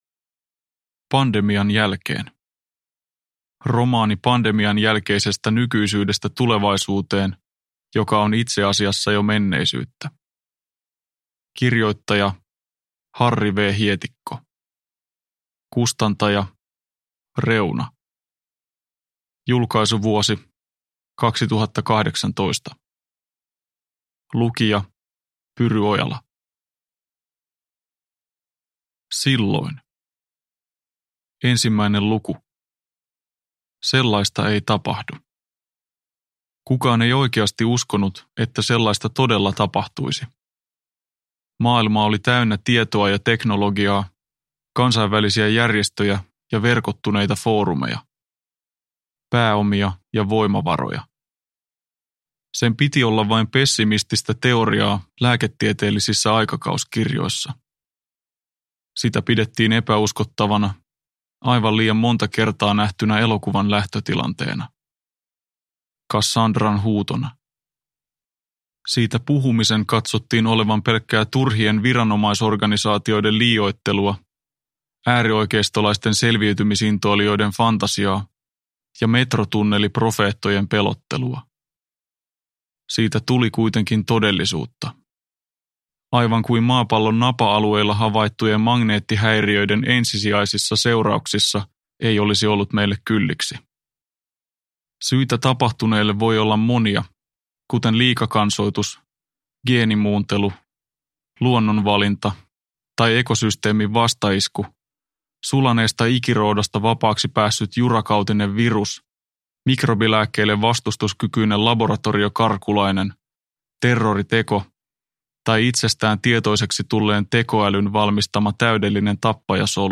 Pandemian jälkeen – Ljudbok – Laddas ner